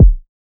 {Kick} pick up.wav